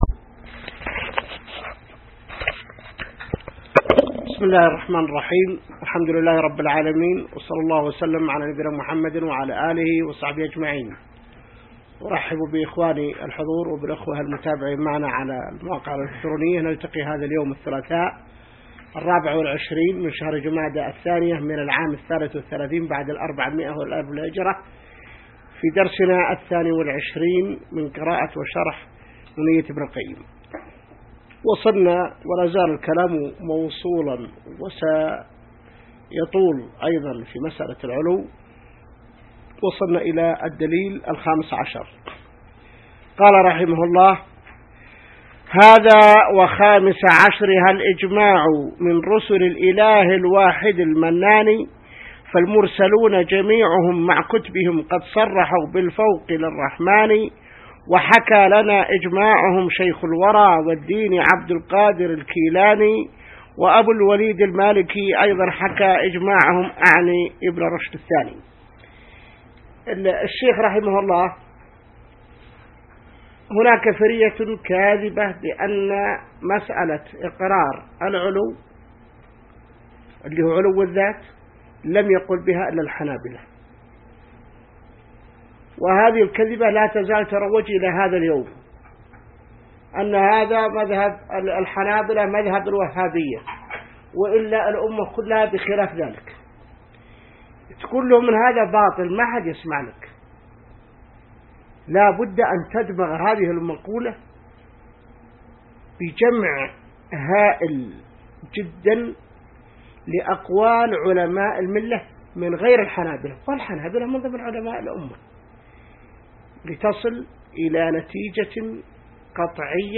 الدرس 22 من شرح نونية ابن القيم | موقع المسلم